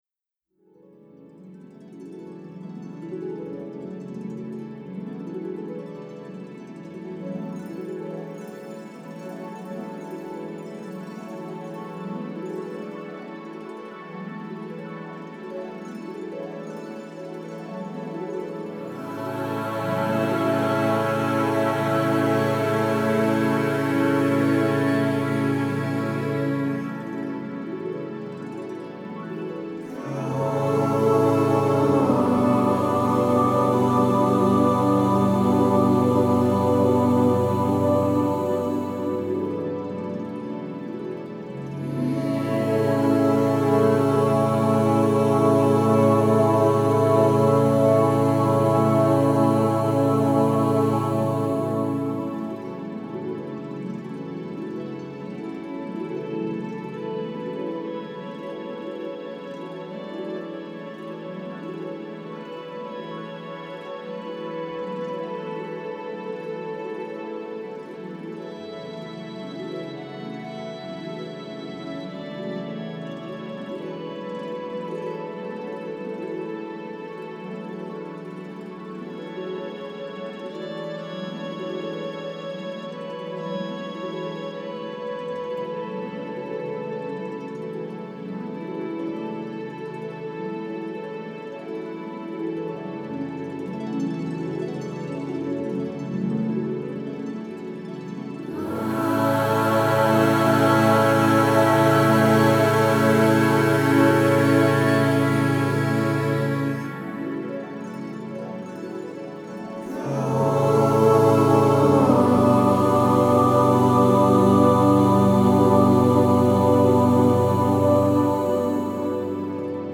CHANTS REIKI